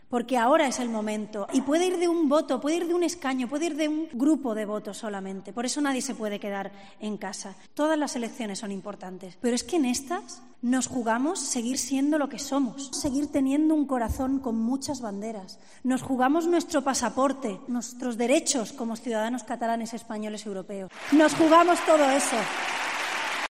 Arrimadas ha participado en un acto de campaña en el Auditorio de Girona junto al presidente de Cs, Albert Rivera